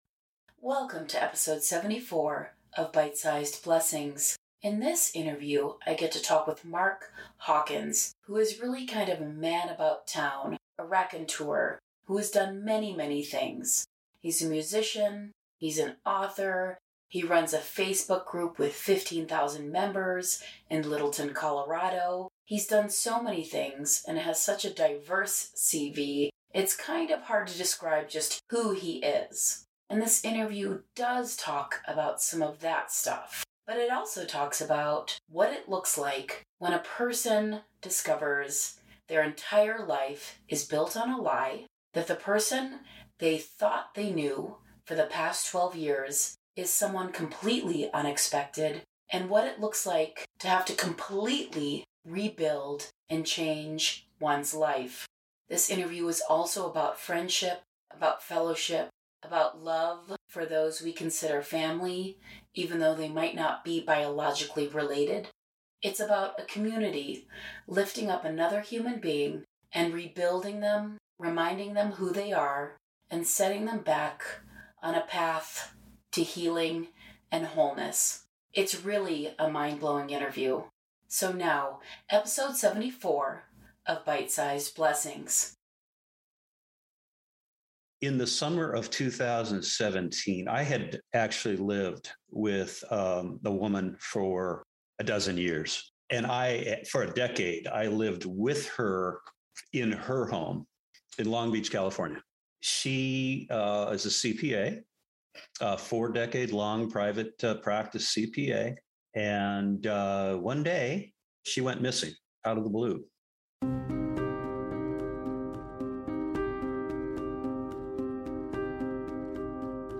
Listen to this longer interview to hear the whole story.